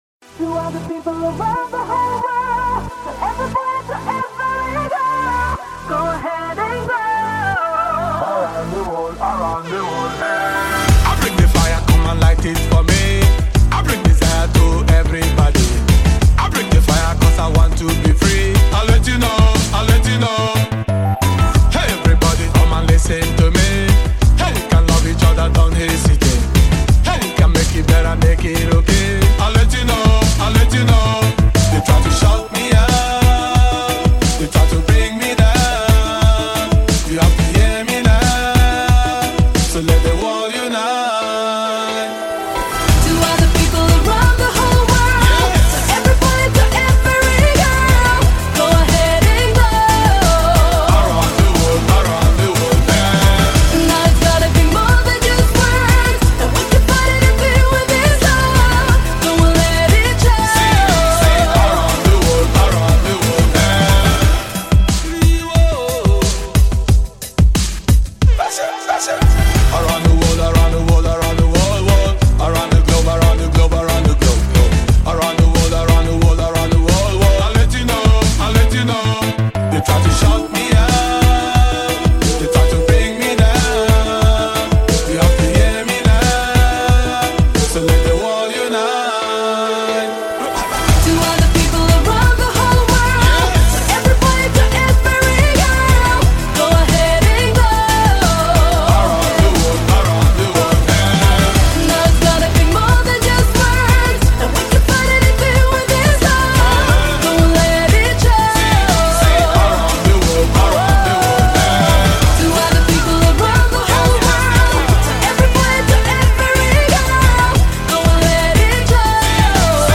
Танцевальная музыка
dance песни